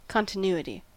Ääntäminen
IPA : /ˌkɒntɪˈnjuːəti/
IPA : /ˌkɑntɪˈn(j)uːəti/